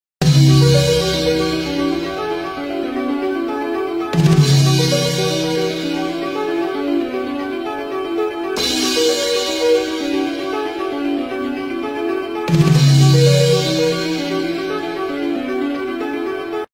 Splitathon-piano-part-hits-hard-TubeRipper.com_-1.m4a